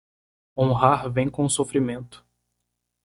Pronounced as (IPA) /so.fɾiˈmẽ.tu/